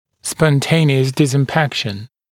[spɔn’teɪnɪəs ˌdɪzɪm’pækʃn][спон’тэйниэс ˌдизим’пэкшн]самопроизвольно исправление положения ретинированного зуба